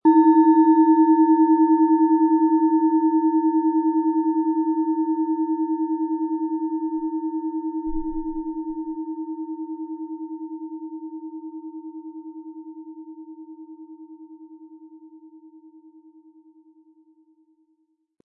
Planetenschale® Verbinde Dich mit allem was ist & Erkenntnisse haben mit Wasserstoffgamma, Ø 11,5 cm inkl. Klöppel
Im Sound-Player - Jetzt reinhören können Sie den Original-Ton genau dieser Schale anhören.
Spielen Sie die Wasserstoffgamma mit dem beigelegten Klöppel sanft an, sie wird es Ihnen mit wohltuenden Klängen danken.
PlanetentonWasserstoffgamma
MaterialBronze